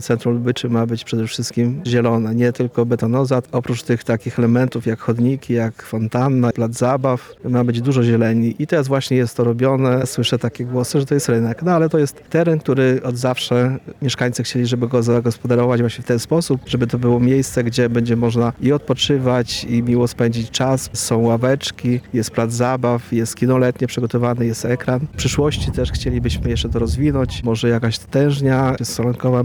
– Pozostała nam tylko kosmetyka i nasadzenia roślinności – informuje burmistrz Lubyczy Królewskiej, Marek Łuszczyński.